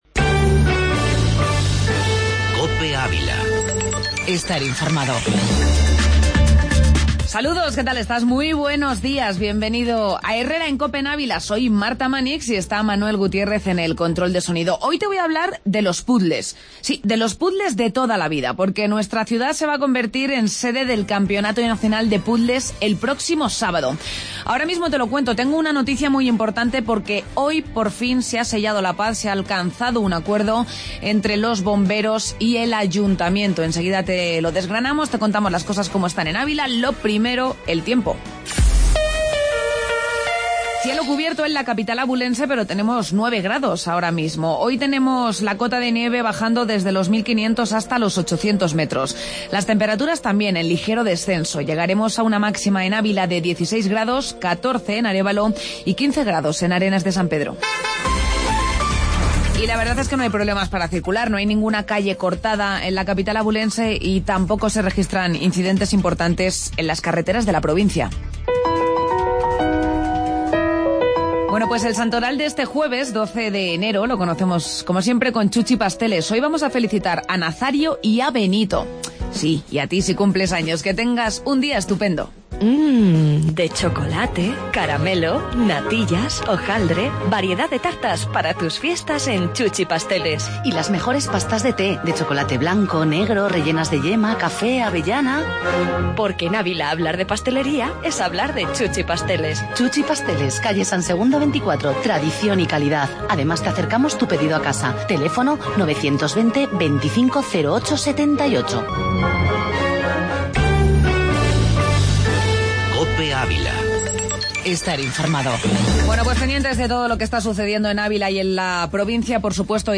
AUDIO: Entrevista campeonato de Puzzles